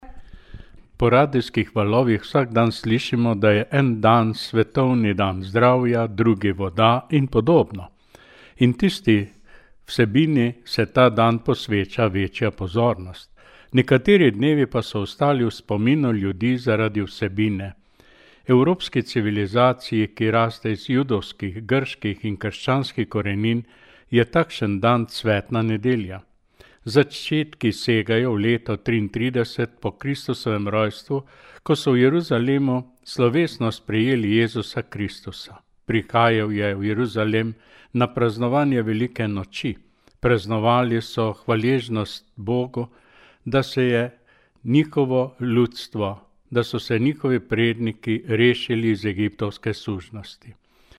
Duhovnik